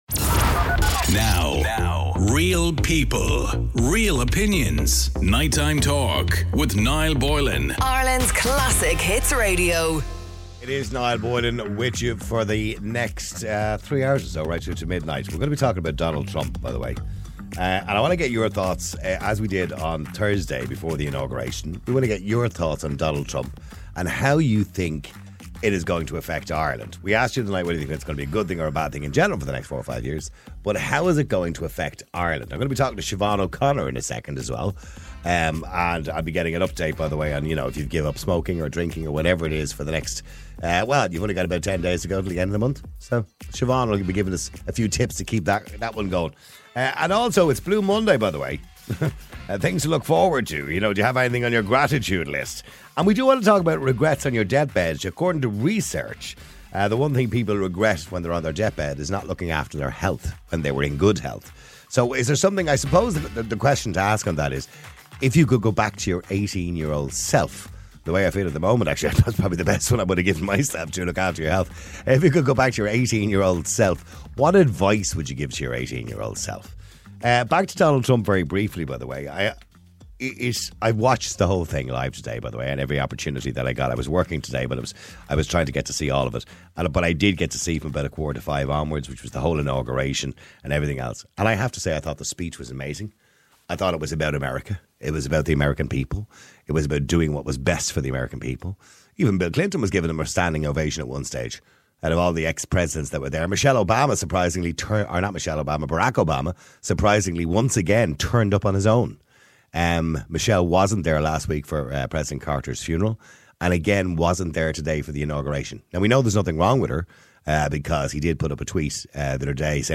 A radio talk show that cares about YOU